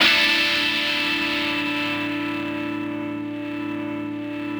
ChordAsus4.wav